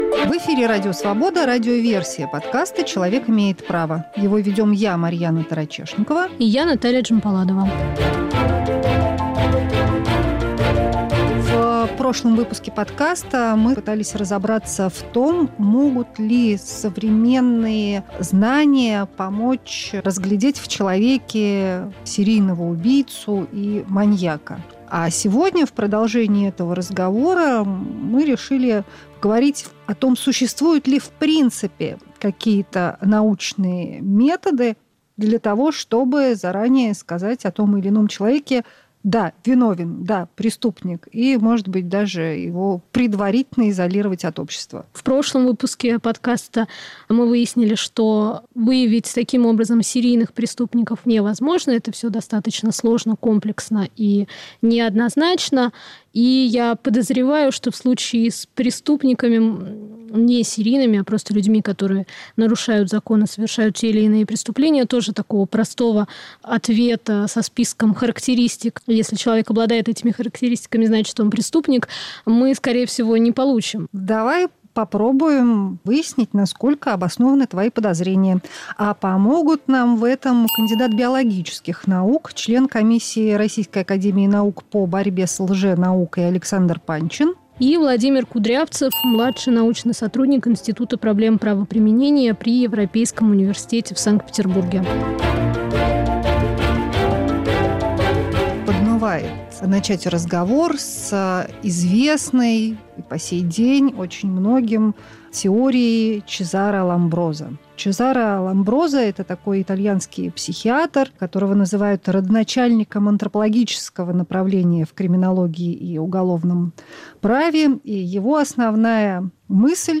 Повтор эфира от 13 апреля 2021 года